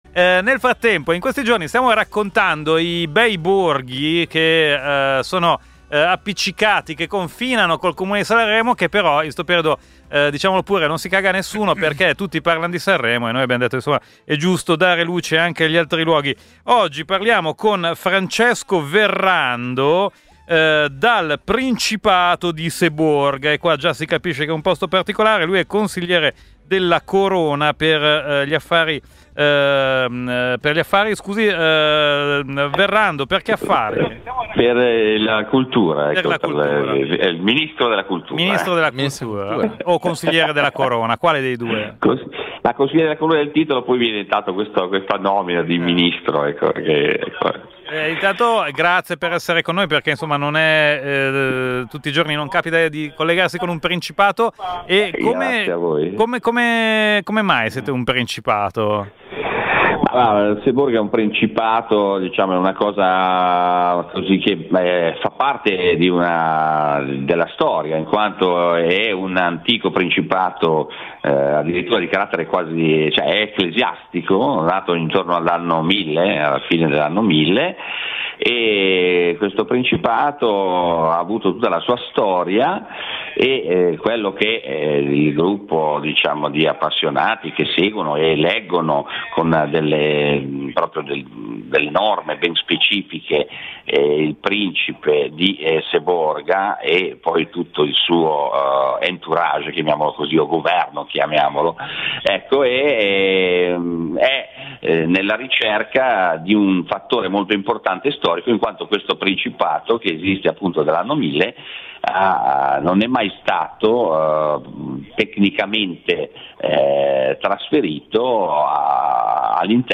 è stato intervistato ieri in diretta su Radio Popolare. In questi giorni il programma della radio “A casa con voi” sta approfittando del Festival di Sanremo per far conoscere ai radioascoltatori anche i paesi vicini alla “Città dei Fiori”, e ieri sera è stato il turno del nostro Principato!